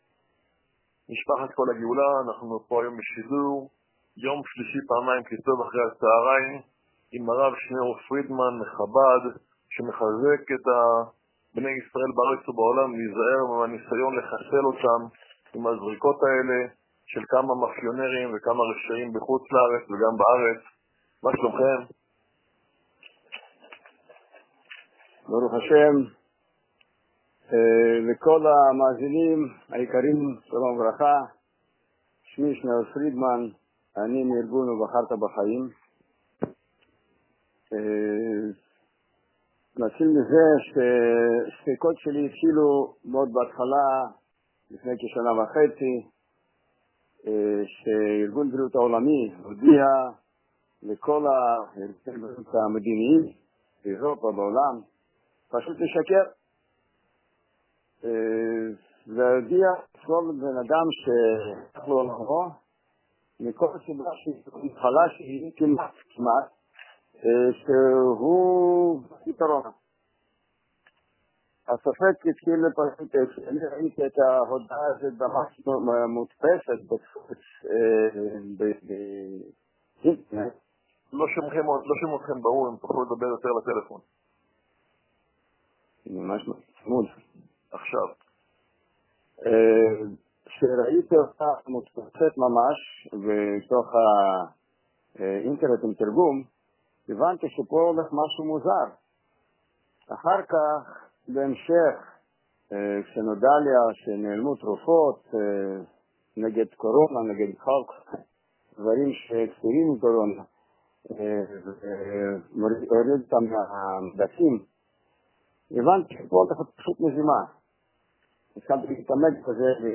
ראיון בקול הגאולה